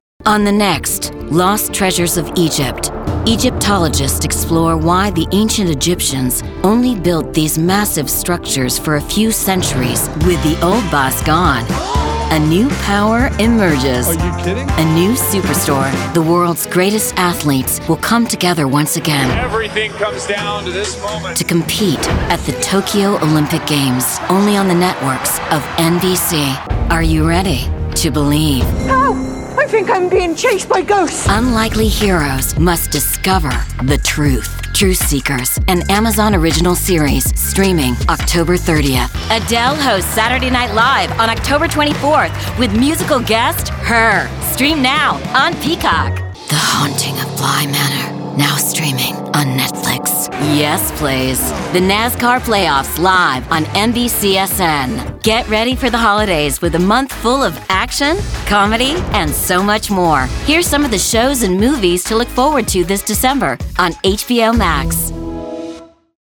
PROMO DEMO
PROMO-DEMO_shorter.mp3